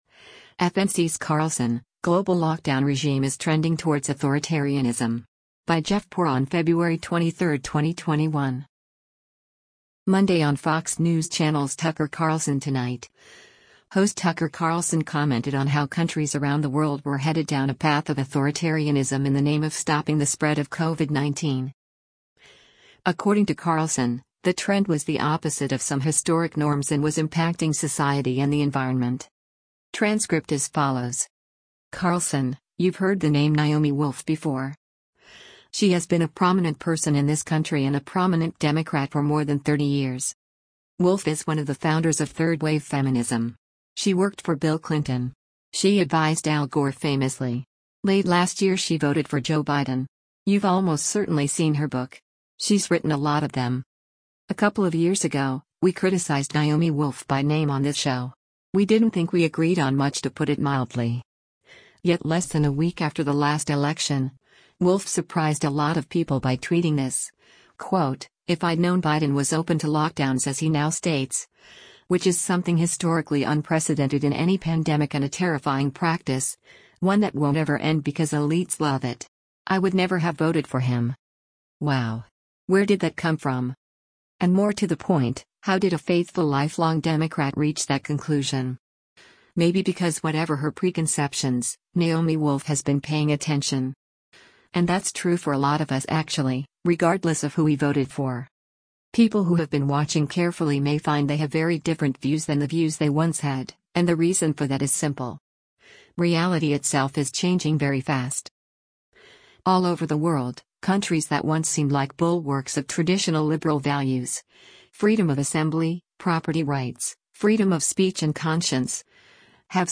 Monday on Fox News Channel's "Tucker Carlson Tonight," host Tucker Carlson commented on how countries around the world were headed down a path of authoritarianism in the name of stopping the spread of COVID-19.